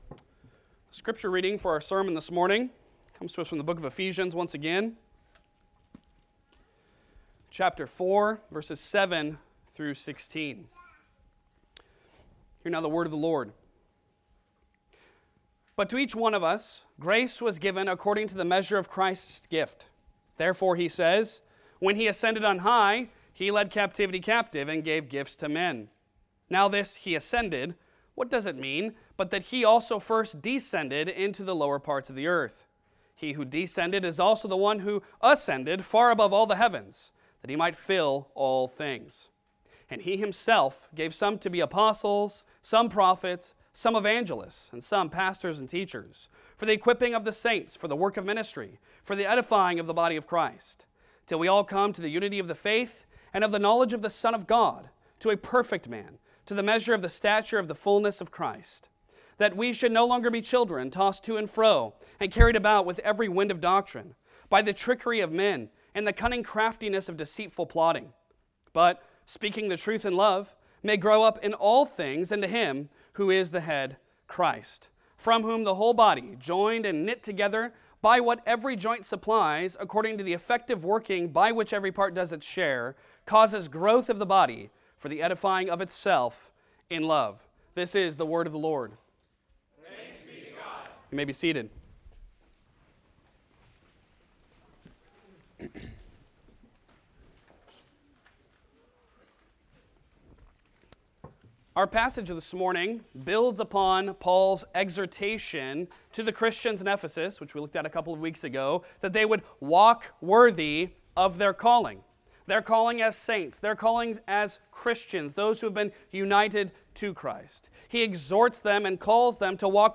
Passage: Ephesians 4:7-16 Service Type: Sunday Sermon